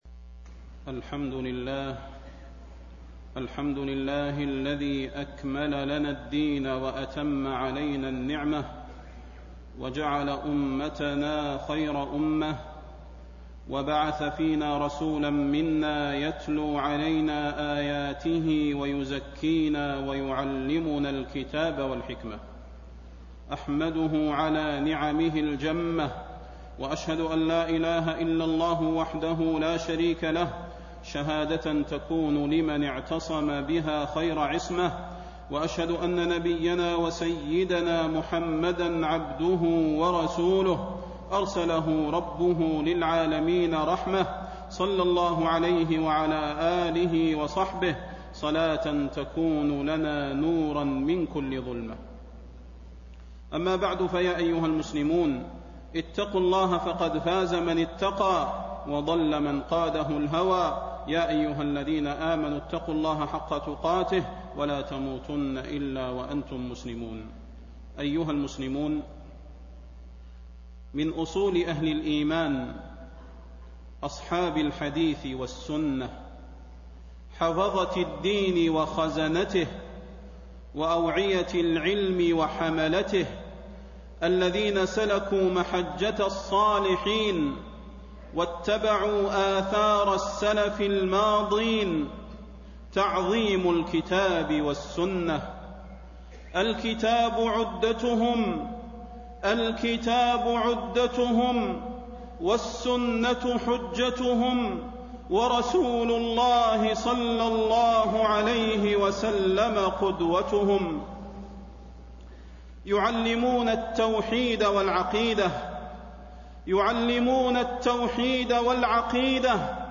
تاريخ النشر ٢١ رجب ١٤٣٤ هـ المكان: المسجد النبوي الشيخ: فضيلة الشيخ د. صلاح بن محمد البدير فضيلة الشيخ د. صلاح بن محمد البدير خطورة رد القرآن والسنة